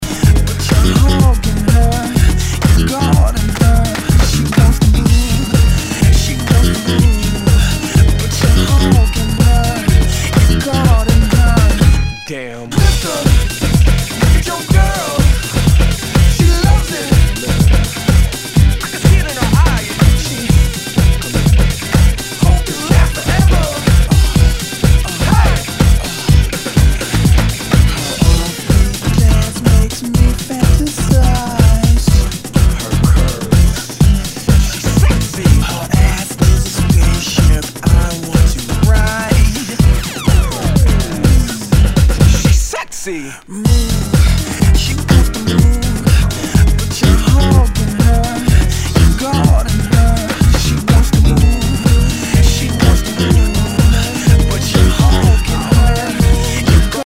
HOUSE/TECHNO/ELECTRO
ナイス！ハウス・ミックス！